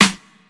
DrSnare25.wav